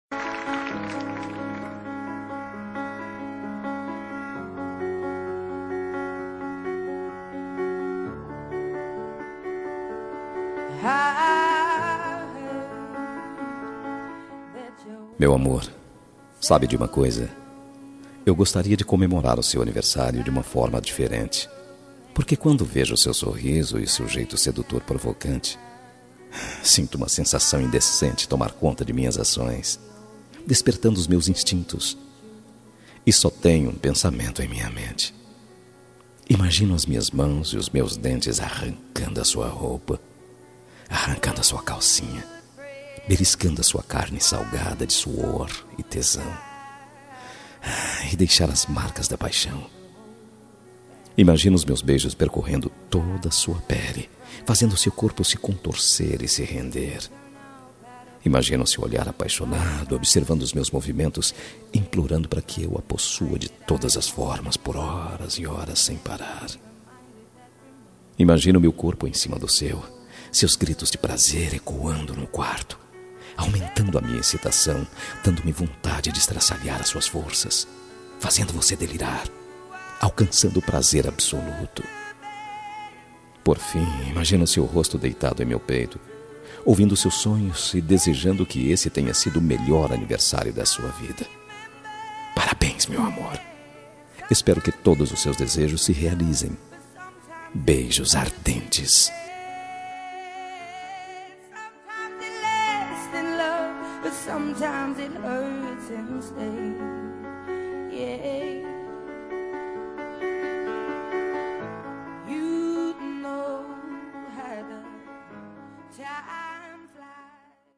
Telemensagem de Aniversário Romântico – Voz Masculino – Cód: 202140 -Picante